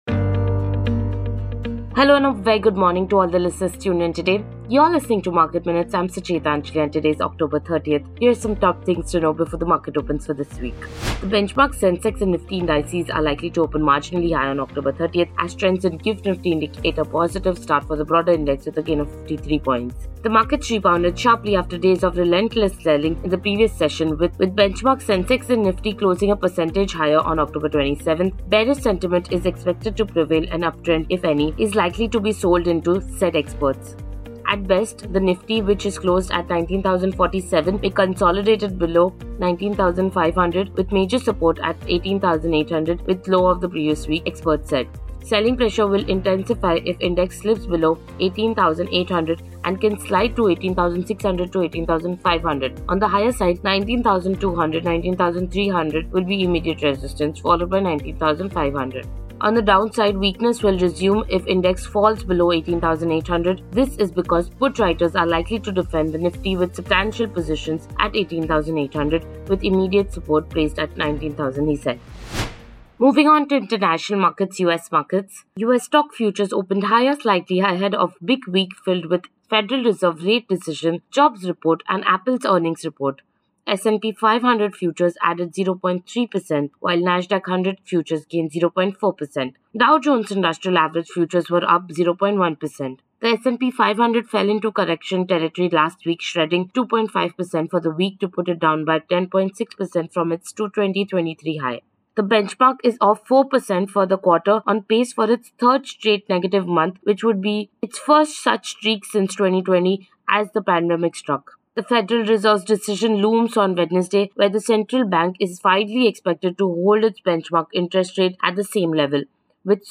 Market Minutes is a morning podcast that puts the spotlight on hot stocks, keys data points and developing trends Share Facebook X Subscribe Next Will India Inc’s corporate earnings season keep investors on the edge?